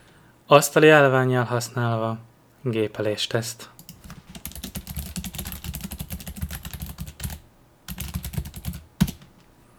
Mennyire hallatszik a gépelés (hangos, mechanikus billentyűzet)
sajat_gepeles.wav